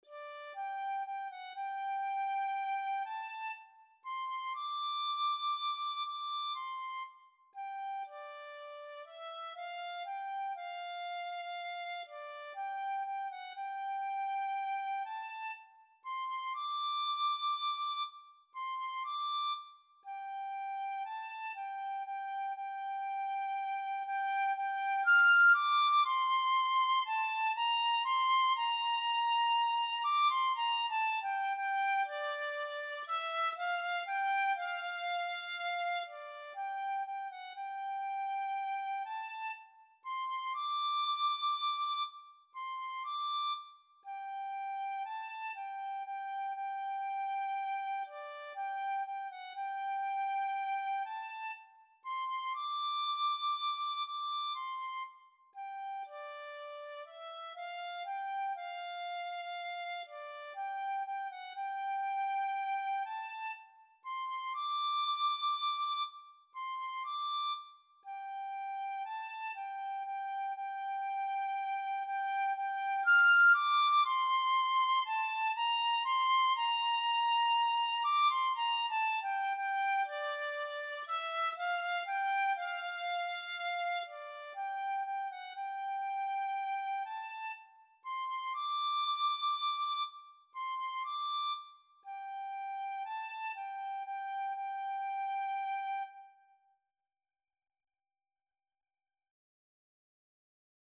Free Sheet music for Tin Whistle (Penny Whistle)
Traditional Music of unknown author.
3/4 (View more 3/4 Music)
Slow, expressive =c.60
G major (Sounding Pitch) (View more G major Music for Tin Whistle )
D6-F7